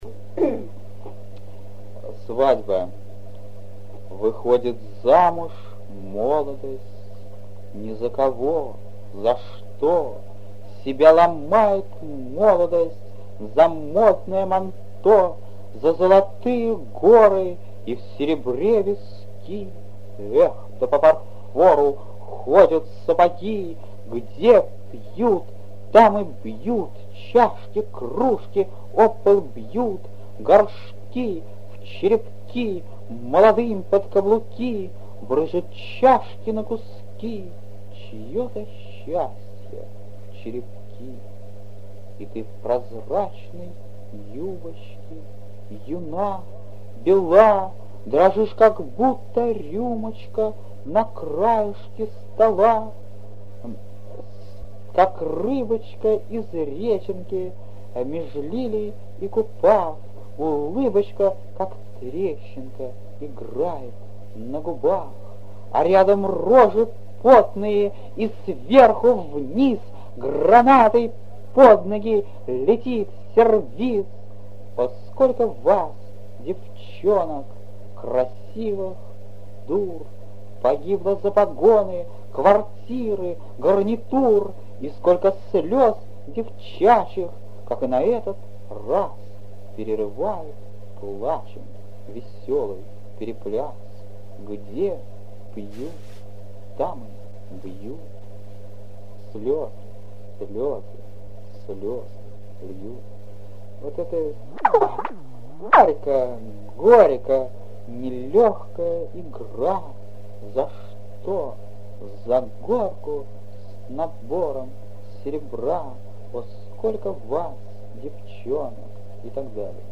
Молодой Вознесенский читает свои стихи.
Вознесенский читал в своей неповторимой манере, нажимая на ударные слоги, а другие, вместе с согласными, растягивая, словно подпевая.